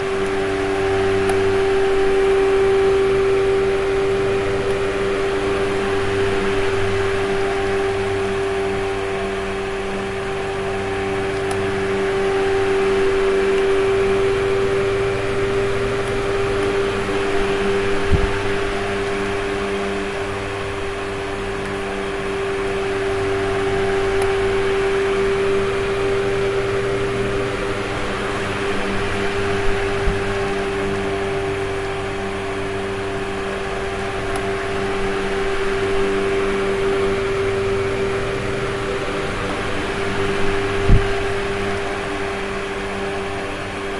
扇子1
描述：一个在我房间里低速运行的ocillating风扇。
标签： 风扇吹 风扇运转 风扇 吹制 运行
声道立体声